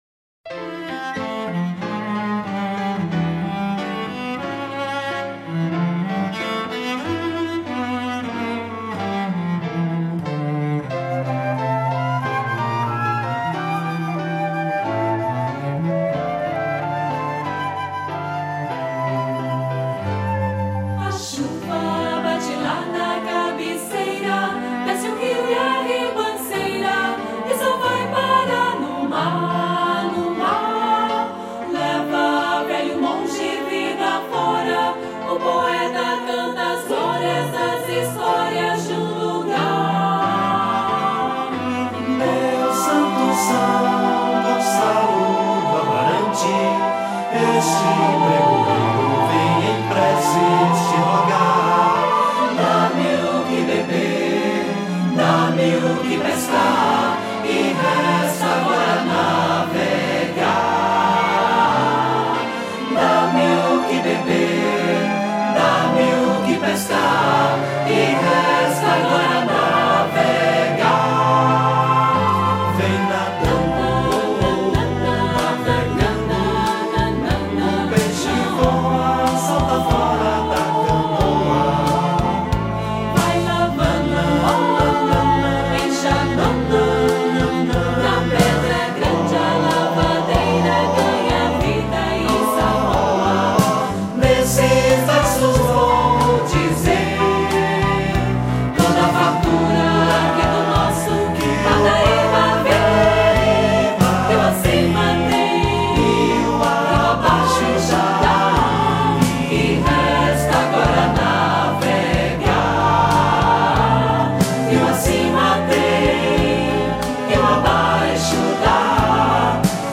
12   03:04:00   Faixa:     Mpb
Violao Acústico 6
Baixo Elétrico 6
Bateria
Teclados
Violocelo
Flauta
Voz SOPRANO
Percussão